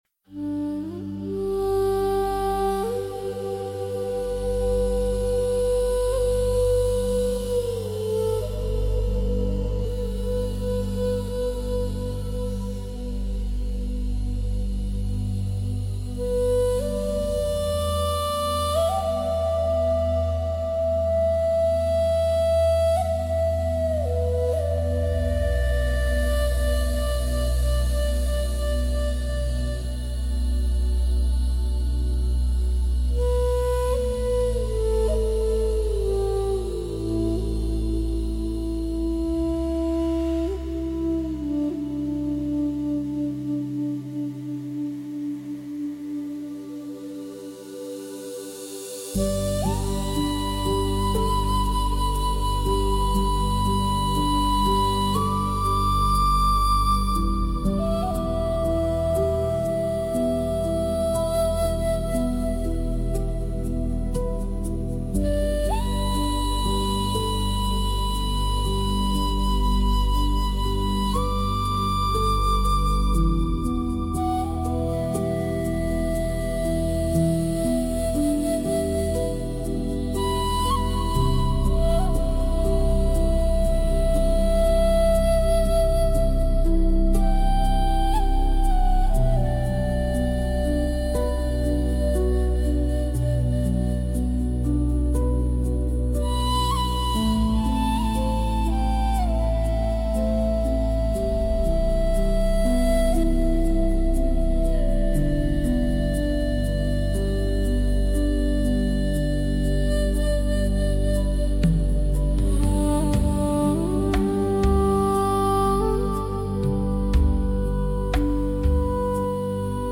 Ambient Flute, Bowls & Nature Meditation Music Soothe your soul and calm your mind with this deeply relaxing ambient track. Featuring gentle bamboo flutes, Tibetan singing bowls, soft atmospheric pads, and the tranquil sounds of nature — all tuned to 432 Hz for natural healing resonance.
💧 Tuning: 432 Hz | Tempo: 55 BPM | Length: Loopable 🕊 Breathe.